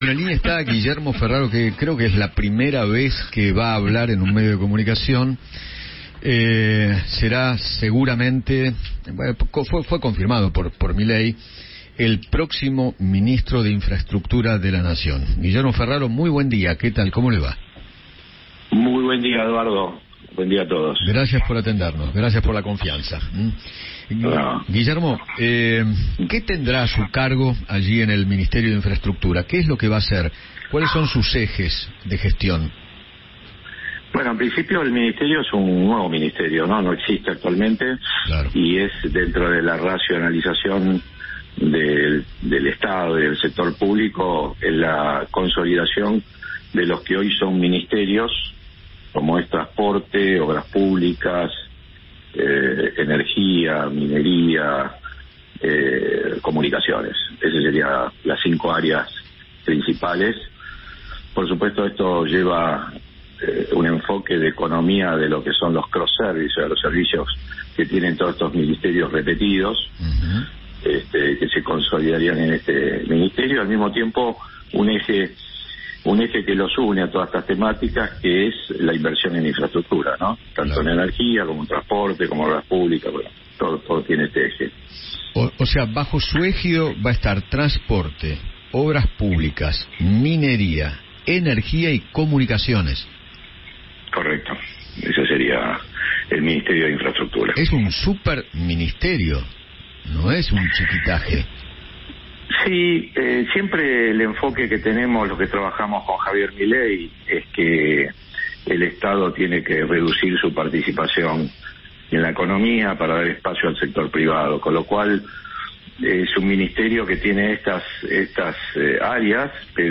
Eduardo Feinmann conversó con Guillermo Ferraro, quien será el ministro de Infraestructura a partir del 10 de diciembre, y detalló sus primeras propuestas.